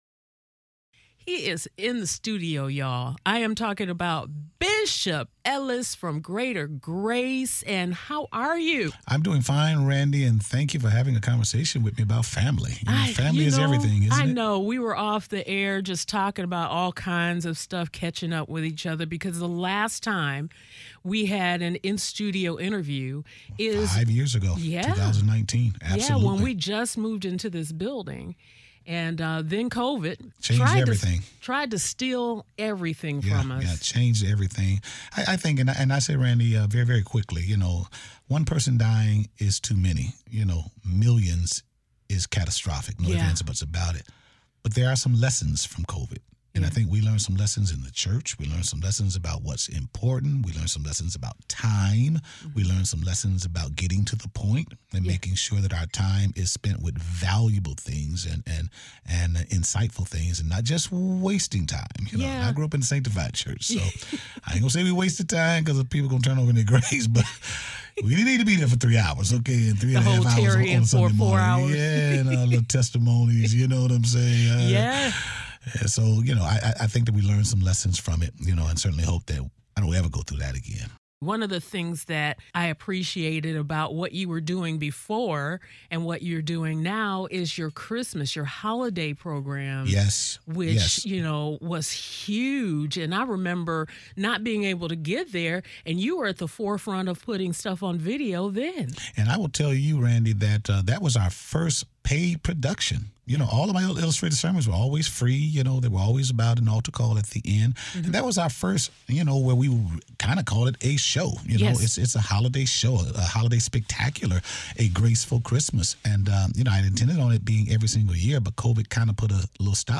Interviews
It’s always a pleasure to have a conversation with Bishop Charles H. Ellis III, Pastor of Greater Grace Temple in Detroit. It’s been a while since he’s  been in the Praise studio, so we had a lot to catch up on.